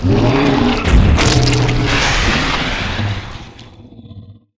Index of /client_files/Data/sound/monster/dx1/
dx1_sandboss1_death.ogg